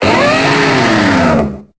Cri de Mammochon dans Pokémon Épée et Bouclier.